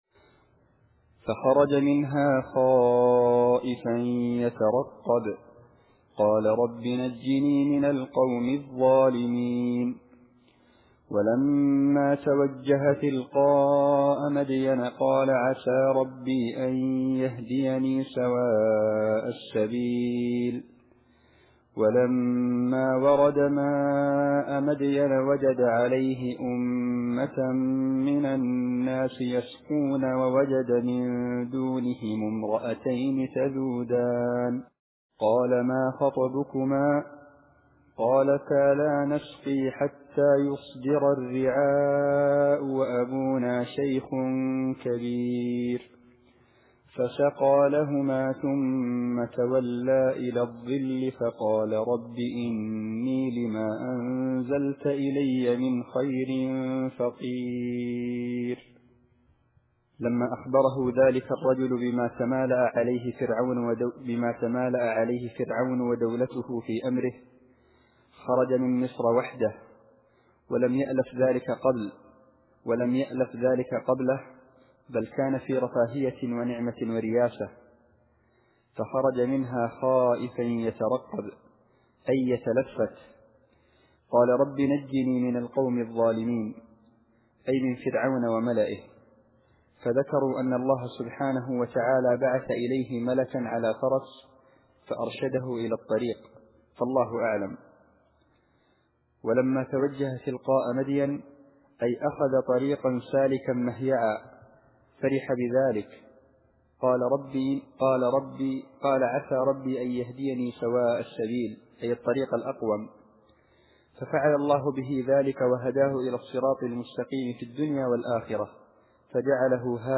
التفسير الصوتي [القصص / 21]